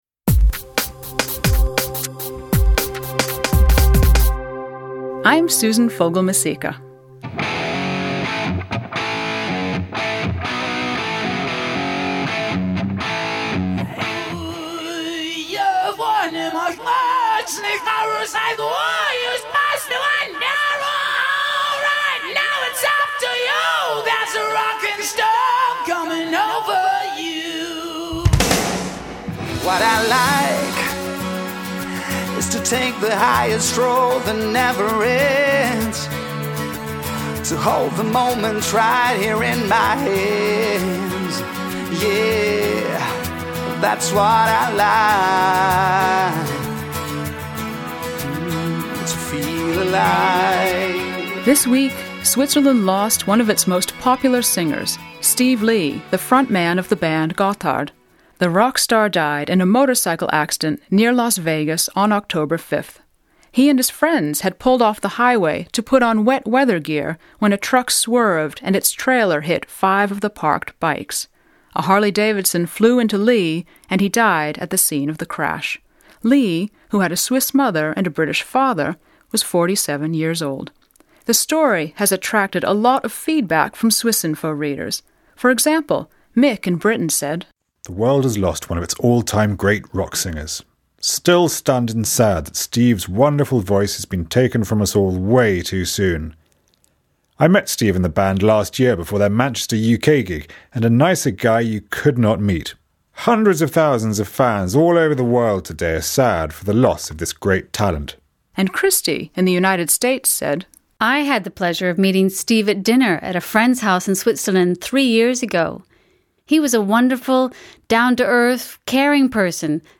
Discussion between woman and man plus music